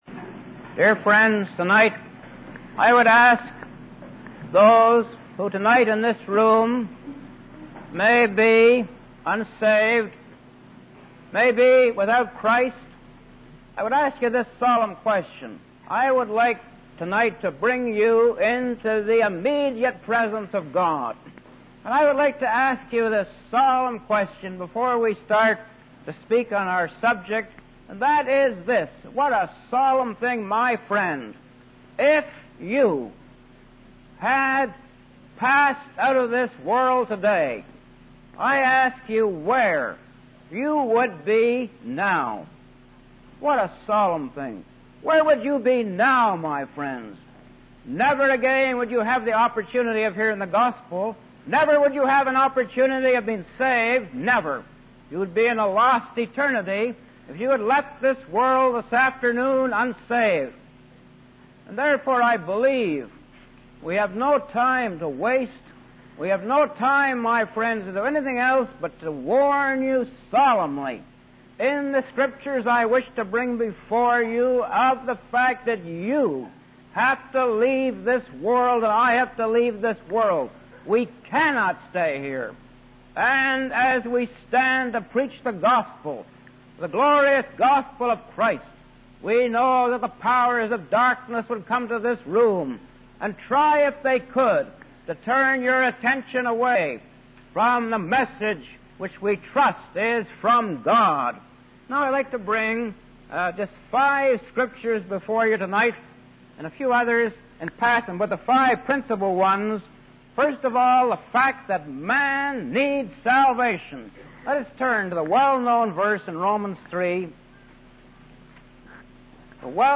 In this 1968 sermon